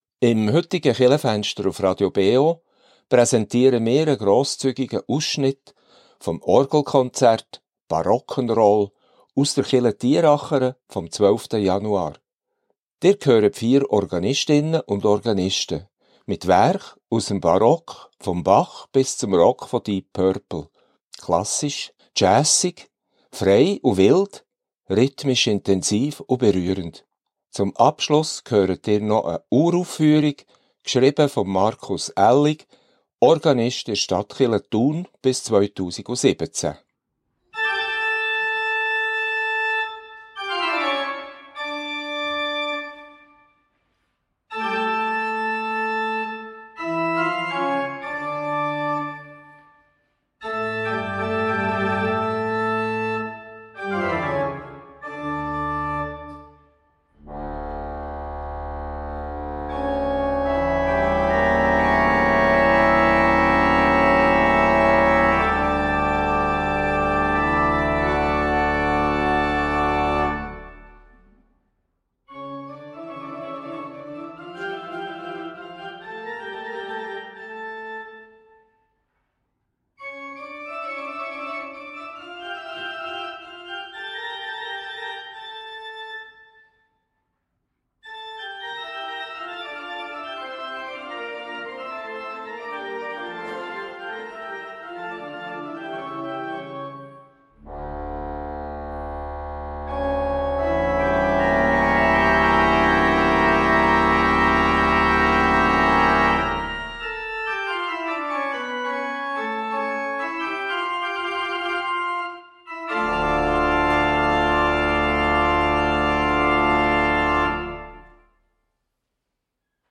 "Barock'n'roll" in der Kirche Thierachern ~ Kirchenfenster auf Radio BeO Podcast
Das vierköpfige Organisten Team
Diesem Lebensgefühl ging das Organisten Team nach, suchte nach rockigen Elementen in der Orgelliteratur und präsentierte ein passendes Programm im Rahmen des Orgelkonzerts vom 12.